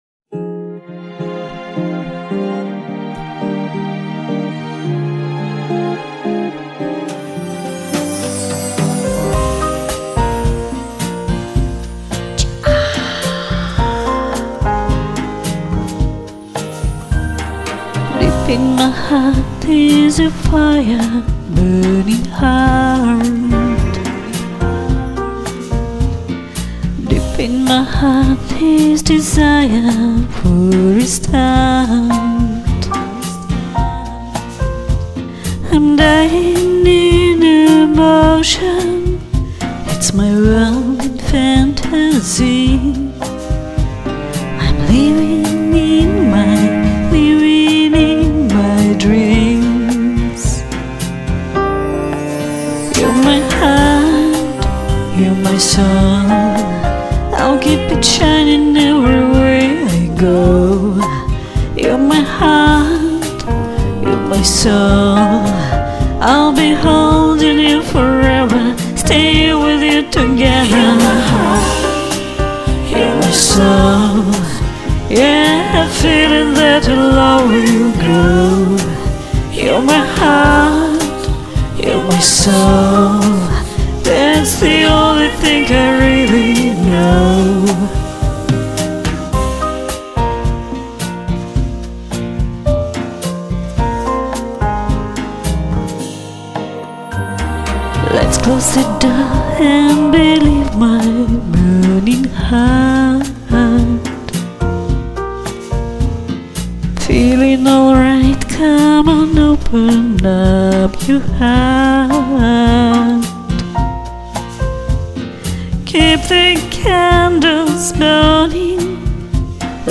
и такая же приятная, качающая аранжировка!